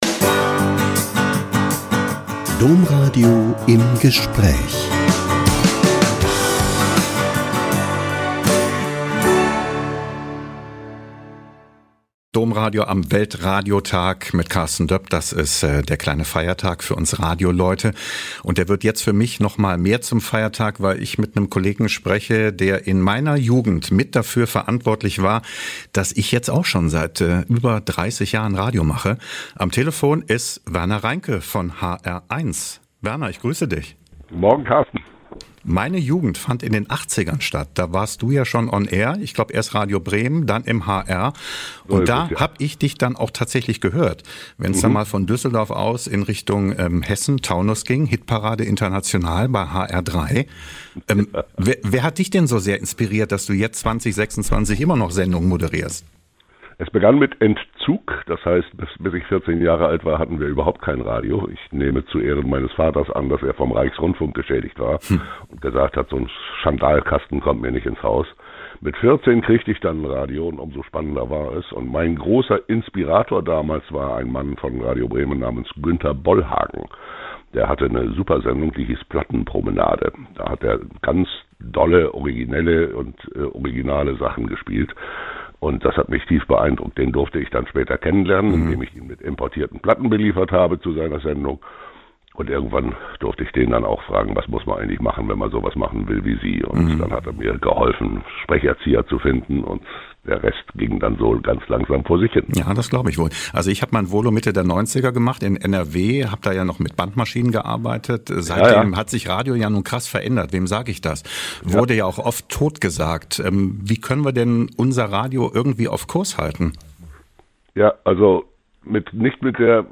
Zum Weltradiotag spricht Radiolegende Werner Reinke über